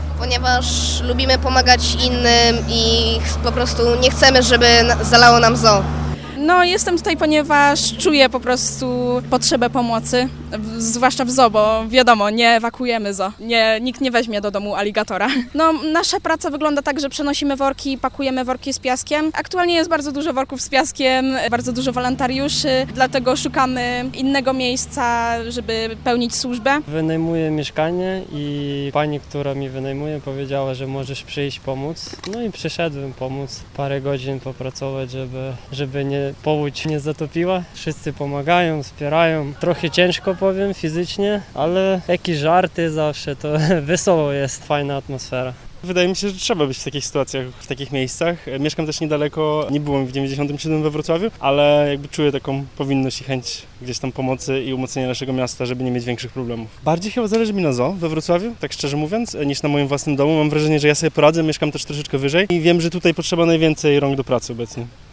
– Zależy mi bardziej na zoo niż na własnym domu – powiedział nam jeden z wolontariuszy. Zapytaliśmy osoby usypujące worki z piaskiem, dlaczego zdecydowały się dziś na pomoc przy ogrodzie zoologicznym.